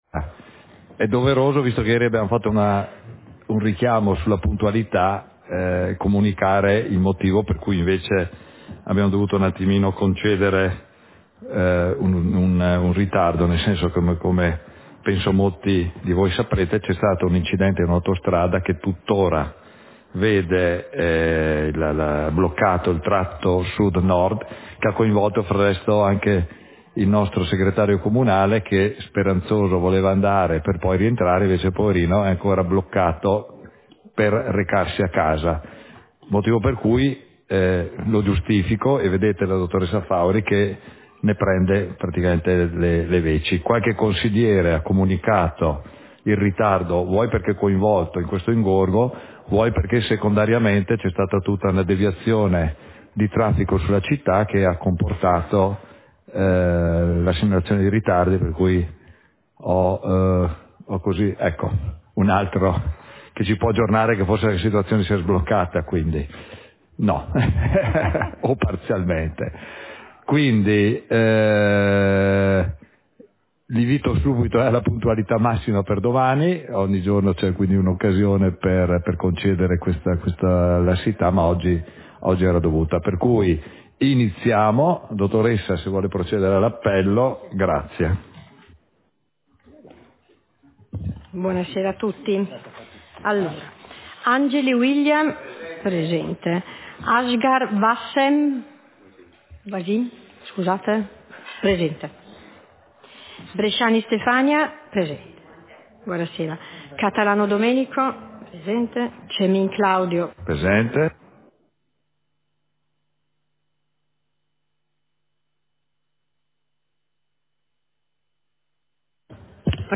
Seduta del consiglio comunale - 11 dicembre 2024